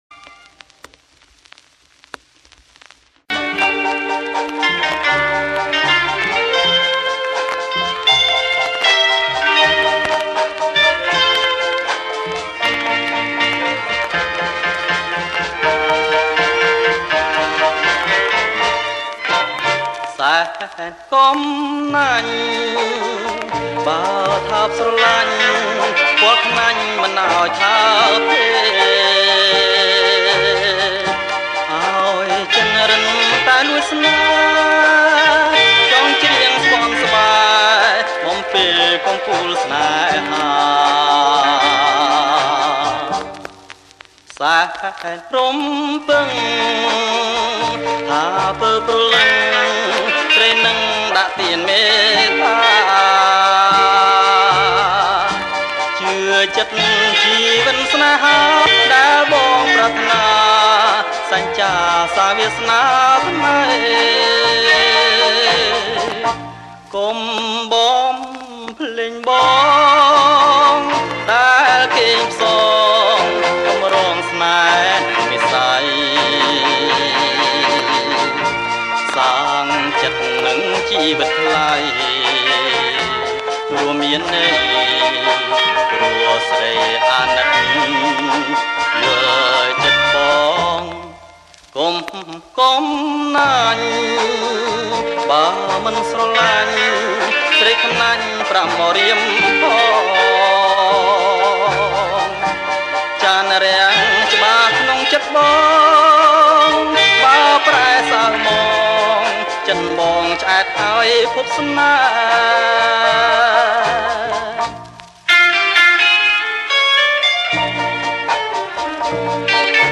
• ប្រគំជាចង្វាក់ Slow Rock